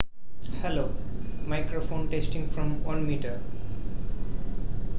正如我前面提到的那样，人的声音是作为一种投入而发出的。
我将在此共享捕获的文件，配置为采样频率为16k，MCLK为4.096M，经过不同范围(米)的测试。
据分析，如果输入信号(人声)超过0.5米，我们不会收到任何溢出中断。